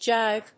/dʒάɪv(米国英語)/